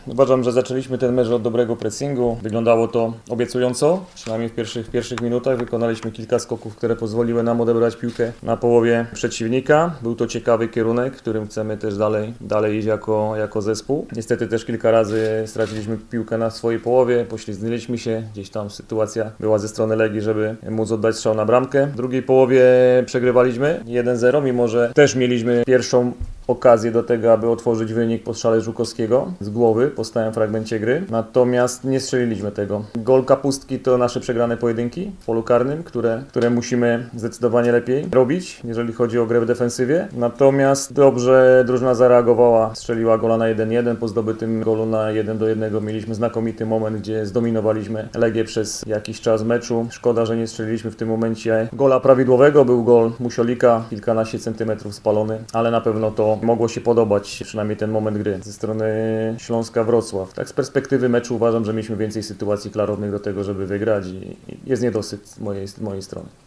– Jest niedosyt – powiedział z kolei Jacek Magiera, trener zespołu z Wrocławia.